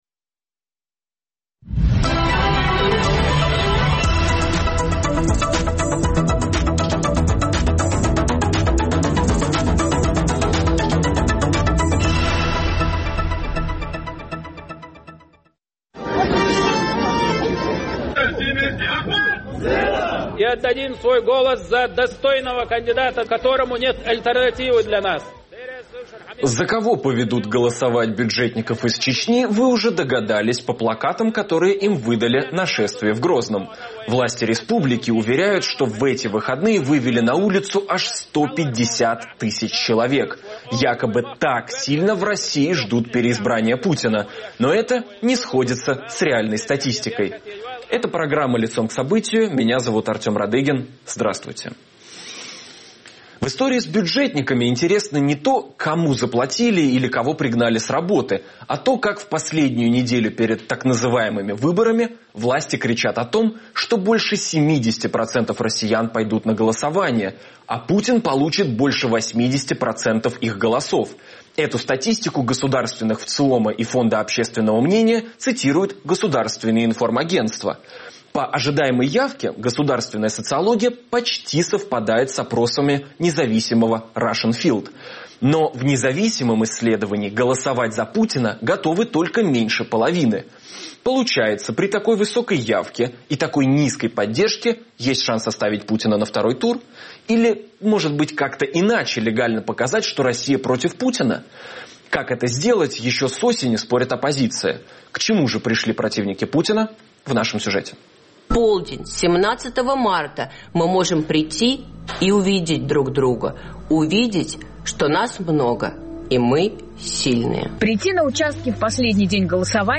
Что происходит в России перед выборами обсуждаем в эфире с политиком Максимом Резником.